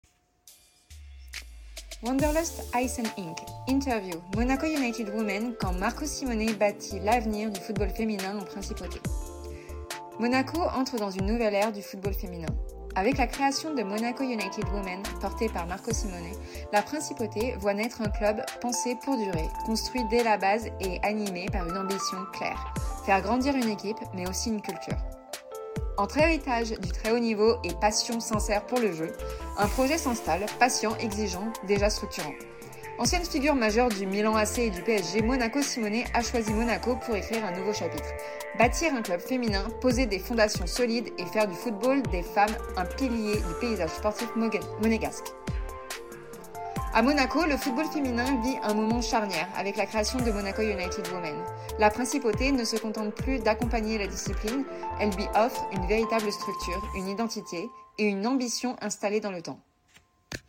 Interview – Marco Simone